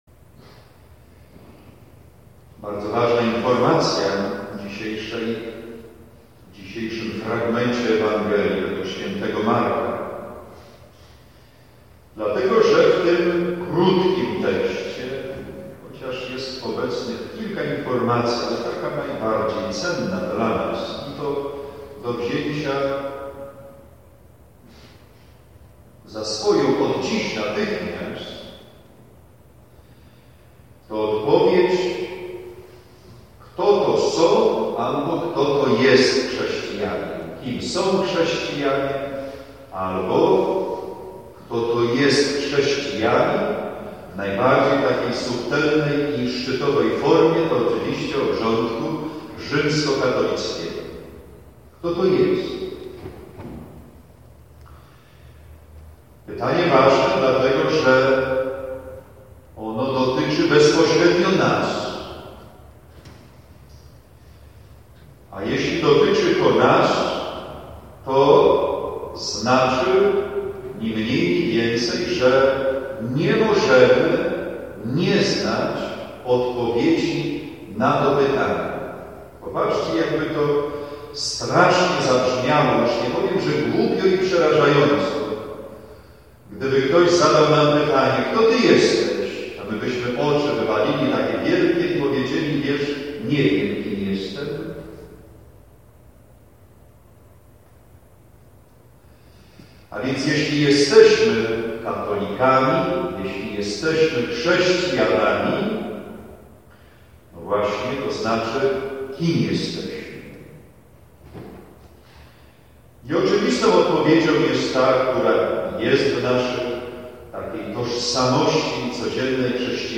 9 stycznia 2017 – Msza św. – homilia